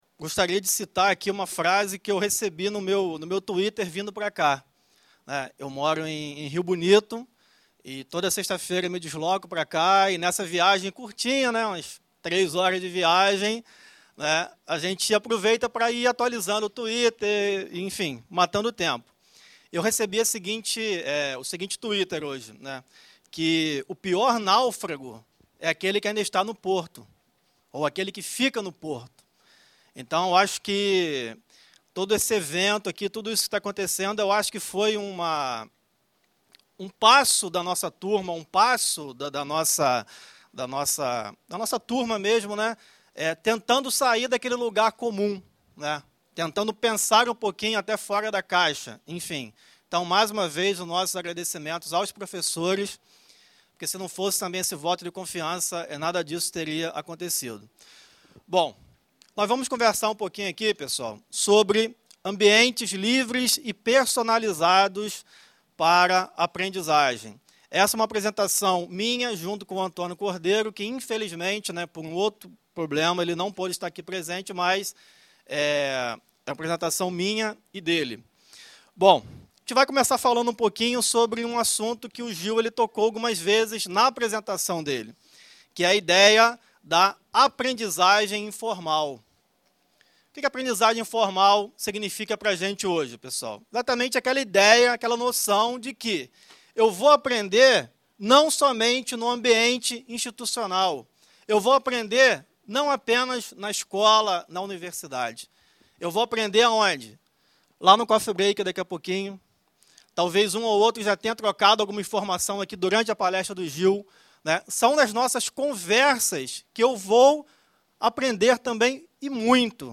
1� Semin�rio PGTIAE: Site Preliminar - 18/11/2011
Palestras